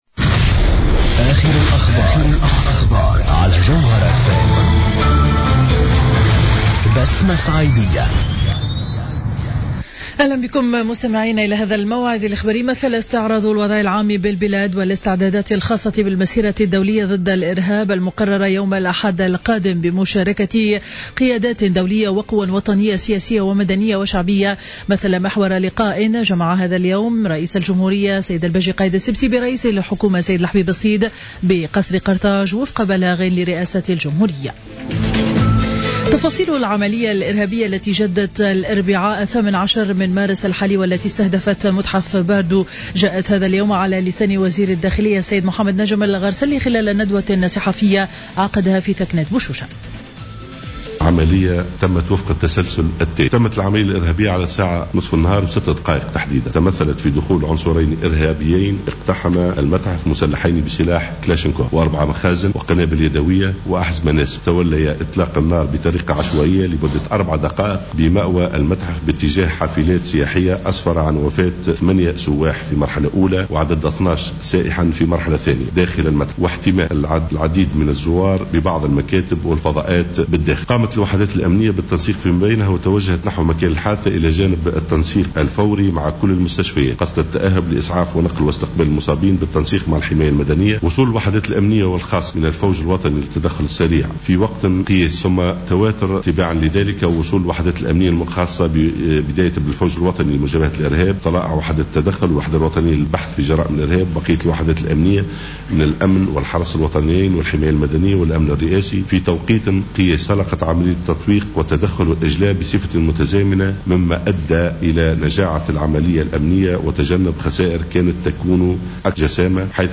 نشرة أخبار منتصف النهار ليوم الخميس 26 مارس 2015